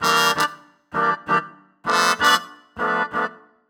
Index of /musicradar/gangster-sting-samples/130bpm Loops
GS_MuteHorn_130-EB.wav